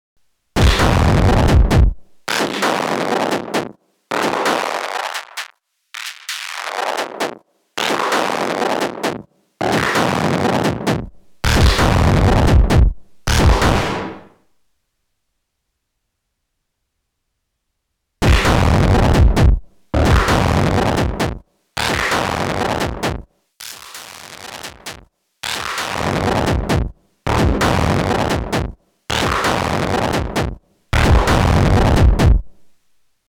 Both work well. First Bass Focus, then Warble’s Base-Width with all warbly-stuff set to zero.